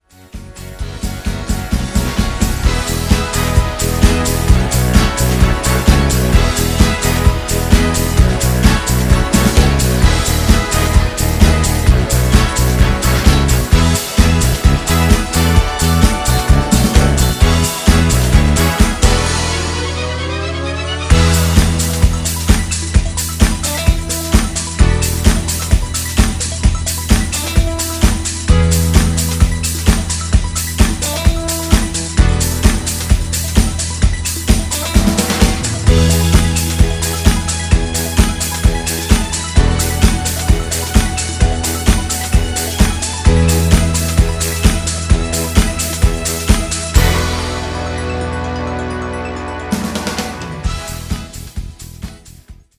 Version 99 extanded mix